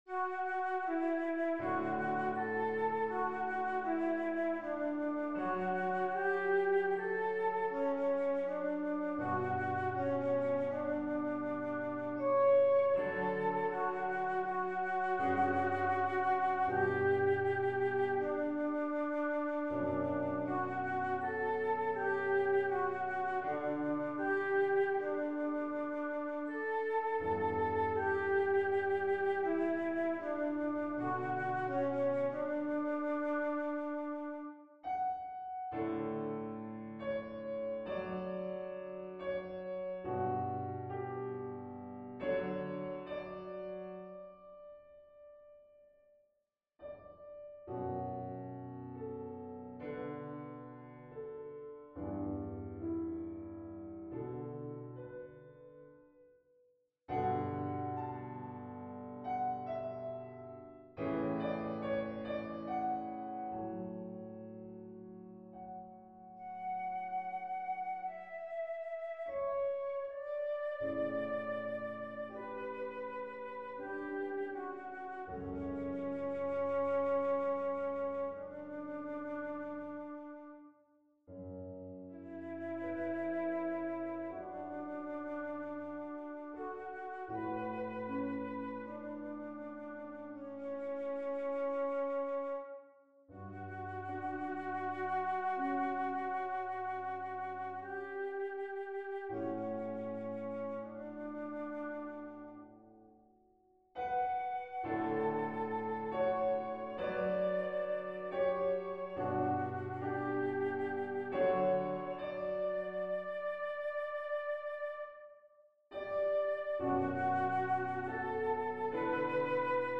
(This is an art song written for Søren Kierkegaard and Regine Olsen using his journals and writings as inspiration. Kierkegaard never married and left everything to Regine who remained a major inspiration in his works.)
(When my microphone comes back from repairs I’ll sing it for you, for now the flute substitutes.)